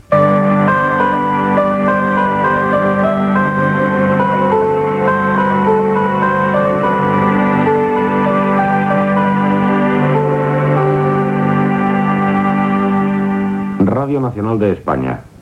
Sintonia de l'emissora i identificació.